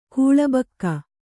♪ kūḷa bakka